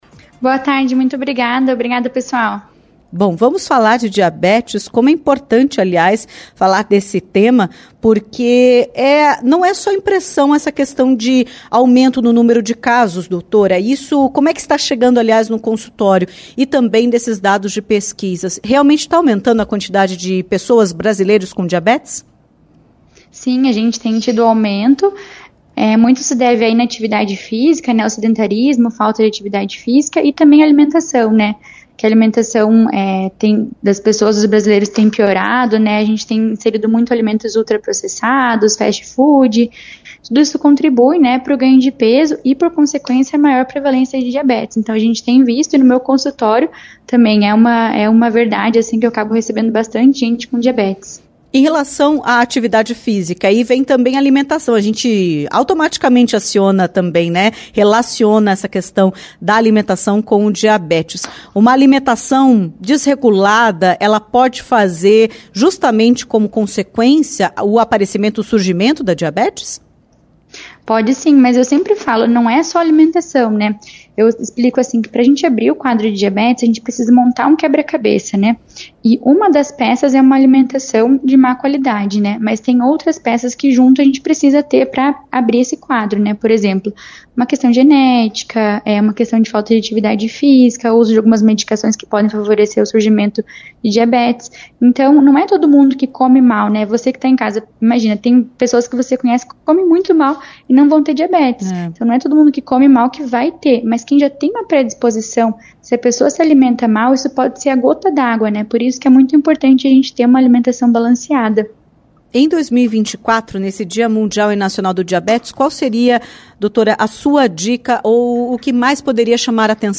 Entrevista-14-11.mp3